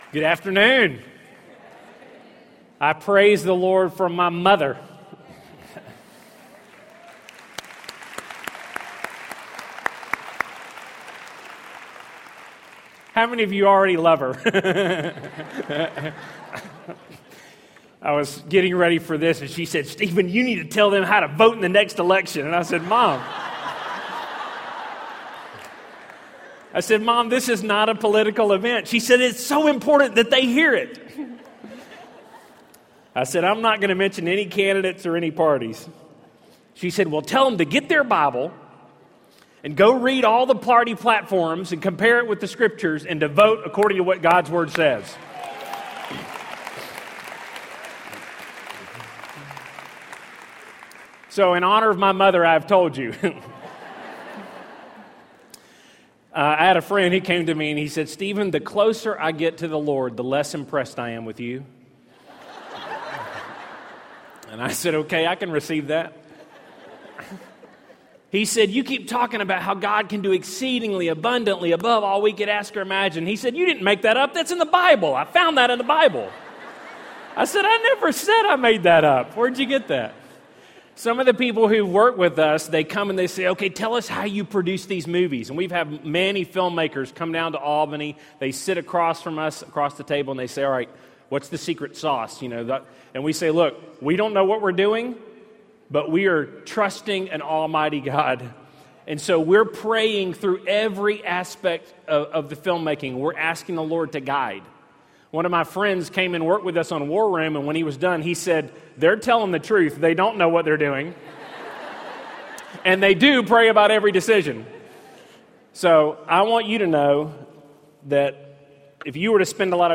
War Room writer and director Stephen Kendrick issues a challenge to make prayer a priority in our lives. As 7,000 women prepare for the Cry Out! prayer event at True Woman ’16, Stephen points to Nehemiah’s example for preparing our hearts to pray. . . and shares how our prayers can be a powerful force in changing our families and our world.